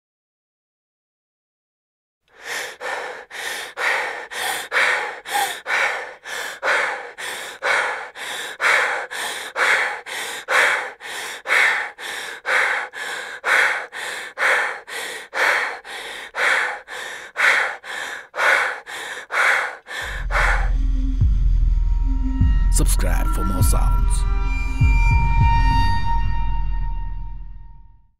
جلوه های صوتی
برچسب: دانلود آهنگ های افکت صوتی انسان و موجودات زنده